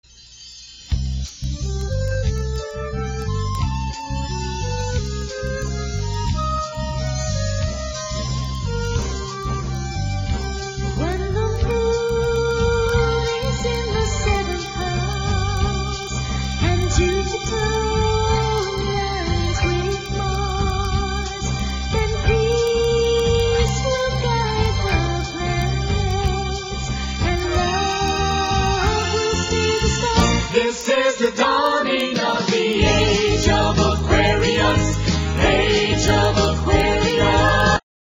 NOTE: Vocal Tracks 1 Thru 6